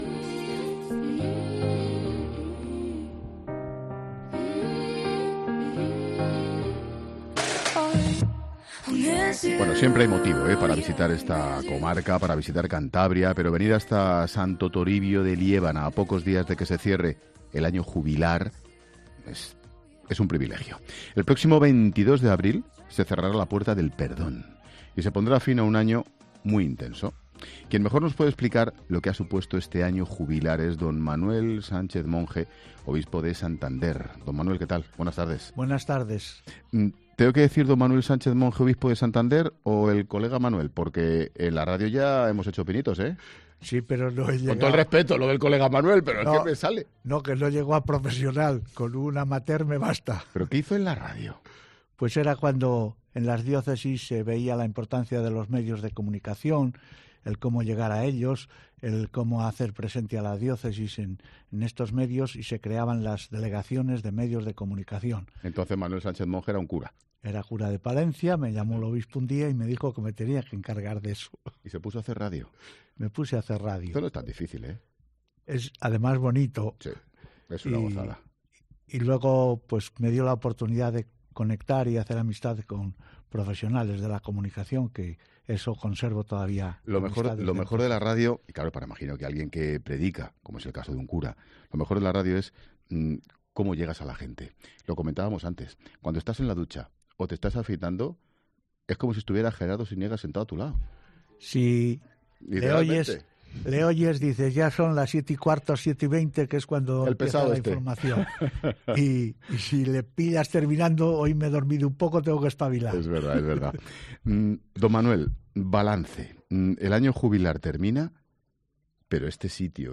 Hablamos con el Obispo de Santander, a escasos días de que se cierre la Puerta del Perdón poniendo fin a un Año Jubilar muy intenso en Santo Toribio...